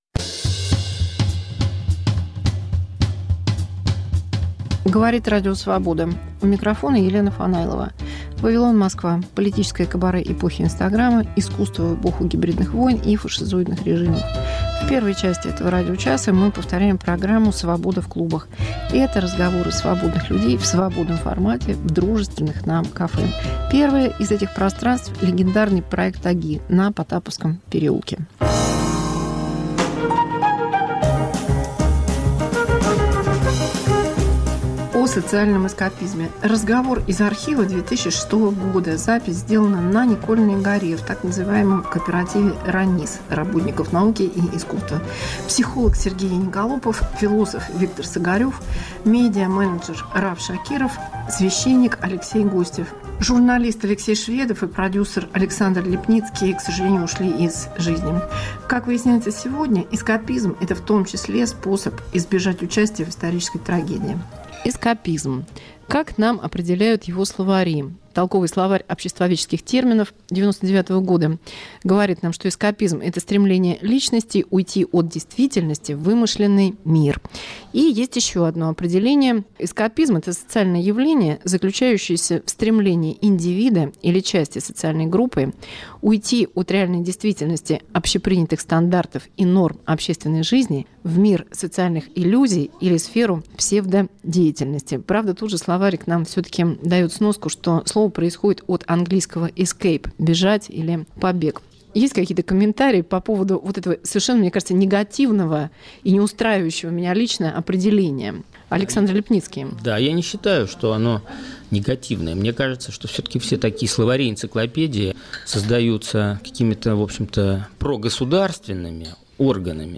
Мегаполис Москва как Радио Вавилон: современный звук, неожиданные сюжеты, разные голоса. 1. Архив 2006: о социальном, политическом и личном эскапизме. 2. 4-я часть антивоенного концерта в Обществе Мемориал